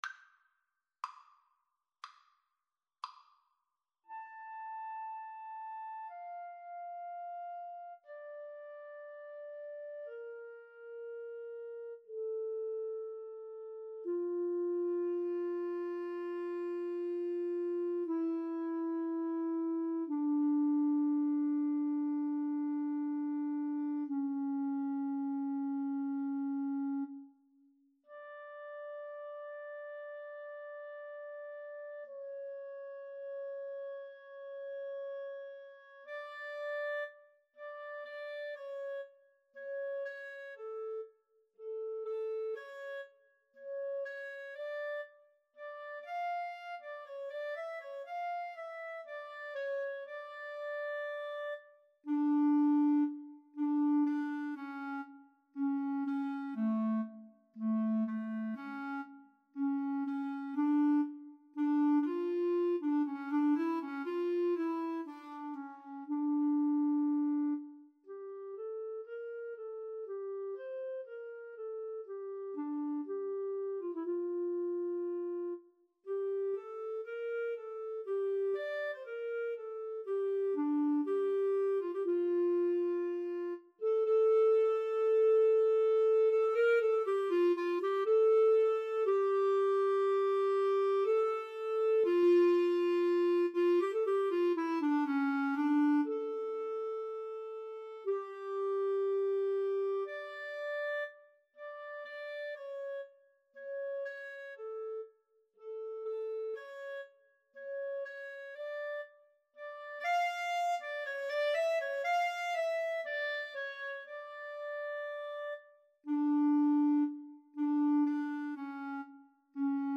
4/4 (View more 4/4 Music)
Adagio non troppo